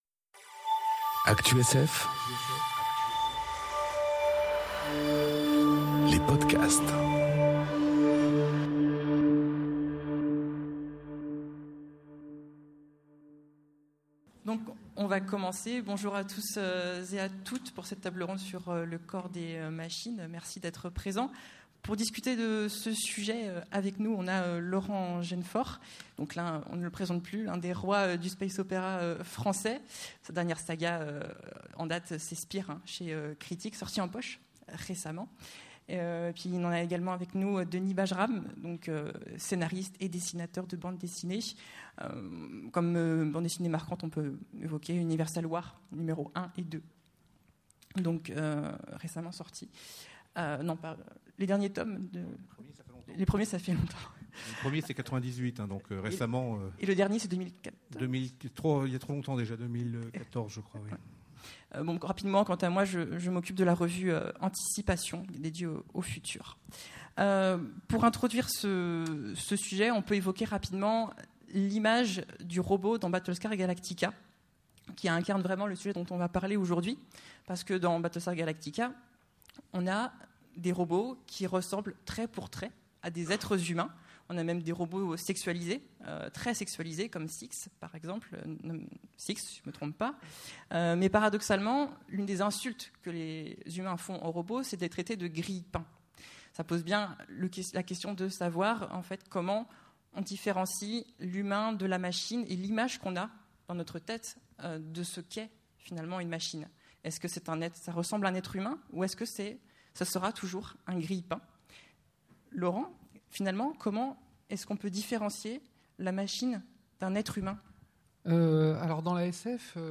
Conférence Le corps des machines enregistrée aux Utopiales 2018